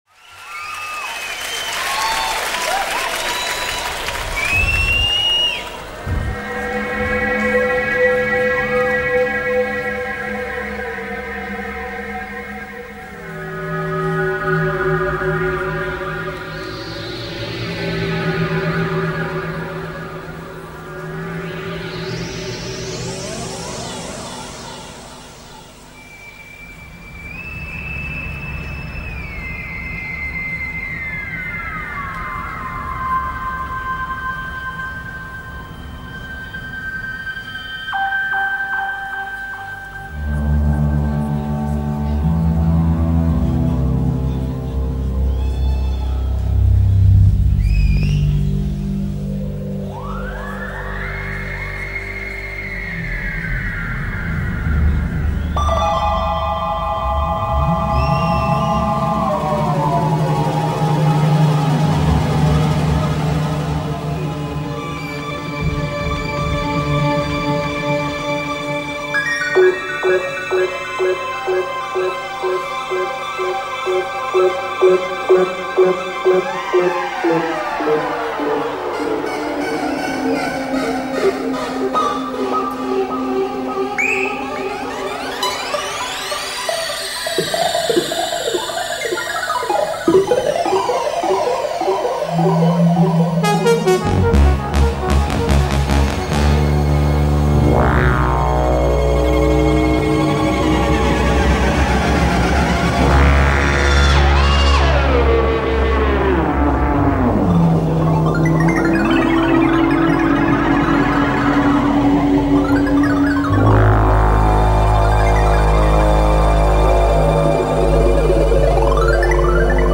in concert from Shepherd's Bush Empire, London
Electronica
Live at Shepherd’s Bush Empire, London
the Psych/Electronic/Downtempo band from Versailles